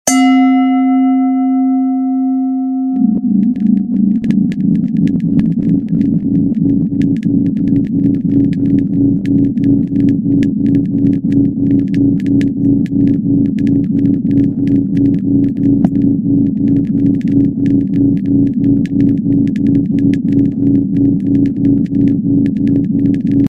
Drift into the deepest sleep with 3 Hz Delta Beats!